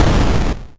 explosioncrunch_000.ogg